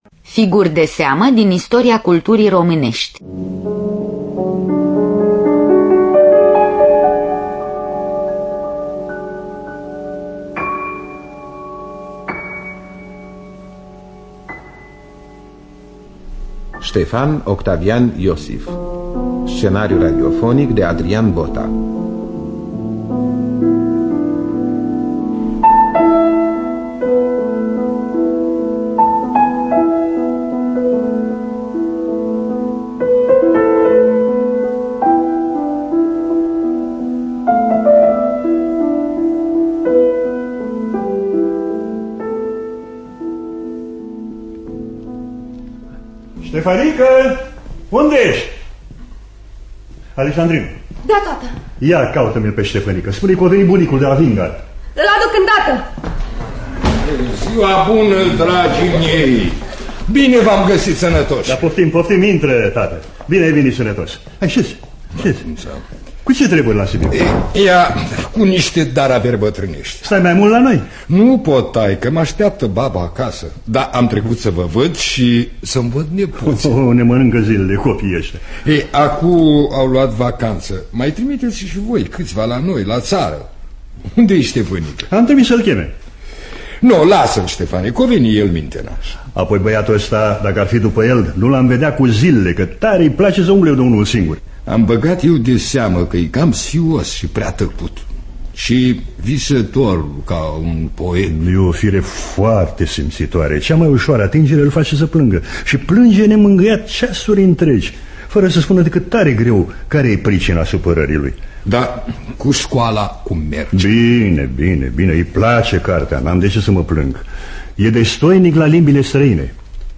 Scenariul radiofonic